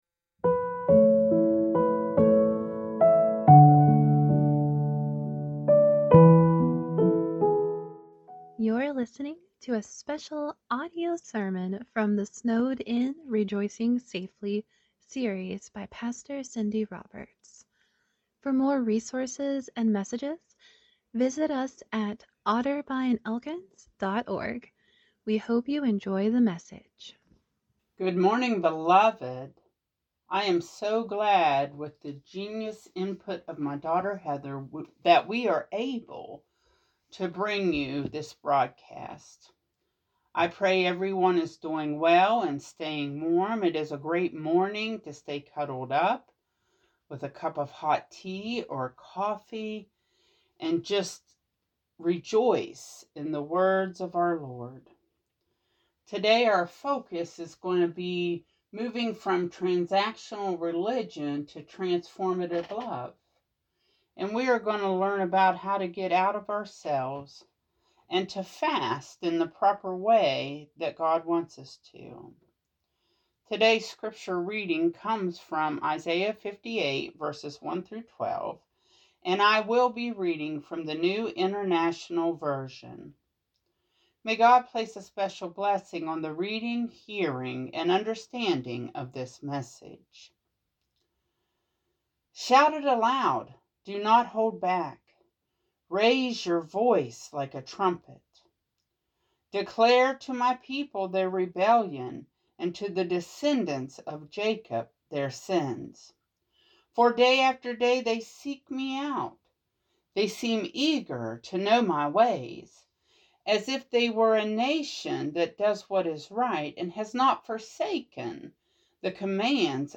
Sermon 02-08-26 | Otterbein | Wayside | Phillip's Chapel |